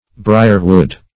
brierwood \bri"er*wood`\ n.